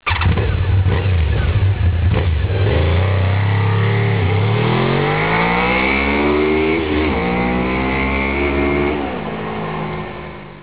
Vous allez trouver tous les sons de bécanes ici, ça va du bruit d'échappement quelconque aux moteurs de sportives en furie, je vous laisse découvrir...
VFR 800 Honda 2
VFR800.wav